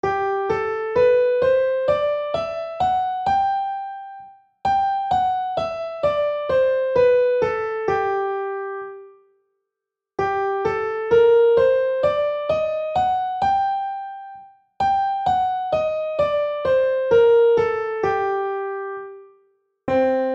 Here are three examples of full octaves of major and harmonic minor scales in the keys of C, G and A, so you can feel the emotional difference between them:
Scales Example 1